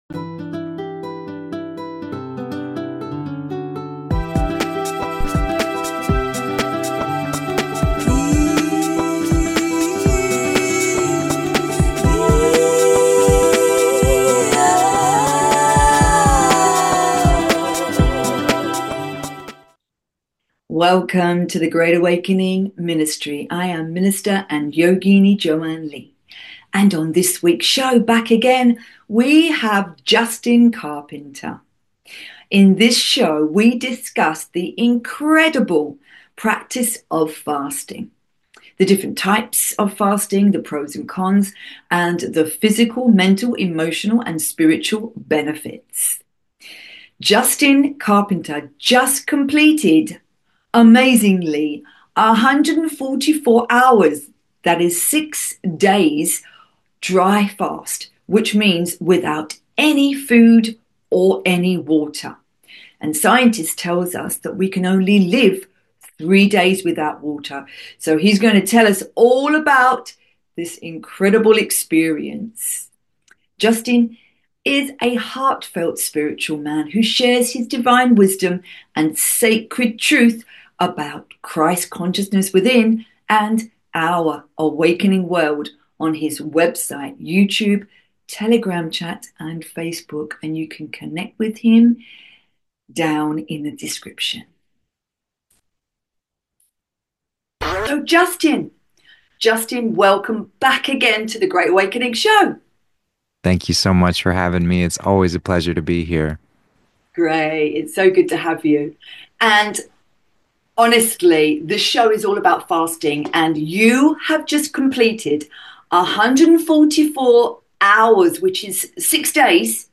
Every show has awakening chats and interviews with incredible people from all around the world; light-workers, way-showers, truth speakers, earth keepers, love embracers, healers and therapists, and all those who are benefiting others and our planet in some way.